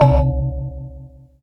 Ambi_Gong.wav